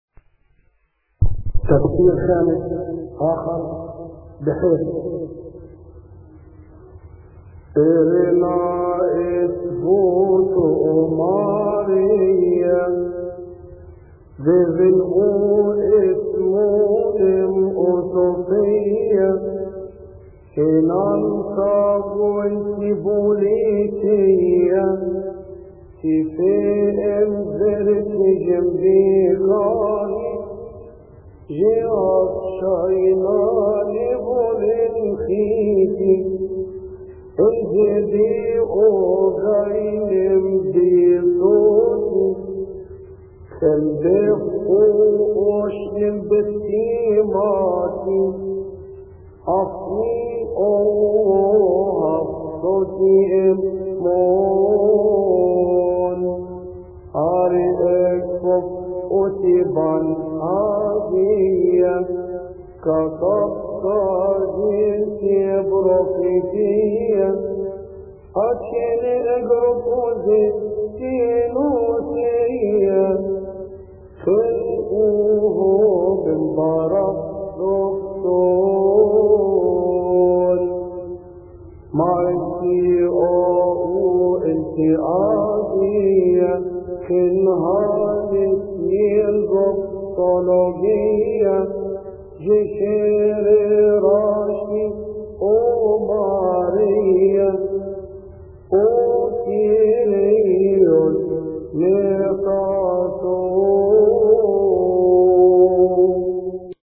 مكتبة الألحان
المرتل